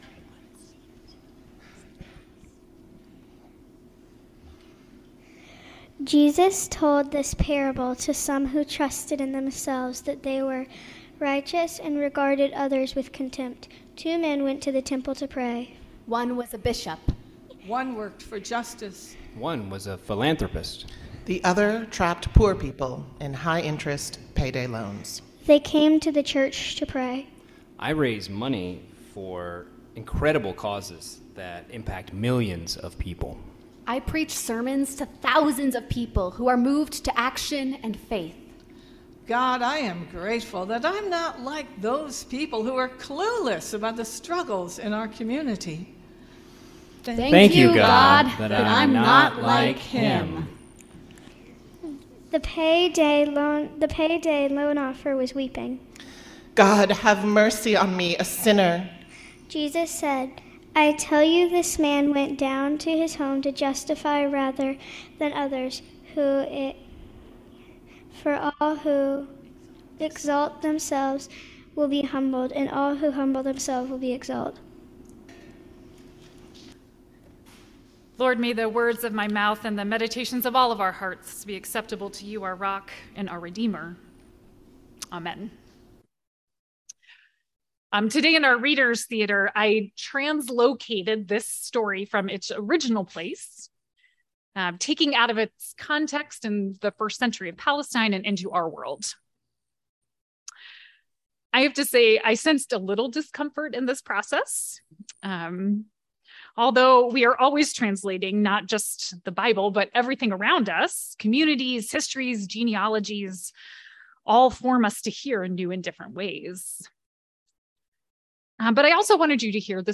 This version as acted out had a Bishop, a philanthropist, an activist and a payday lender.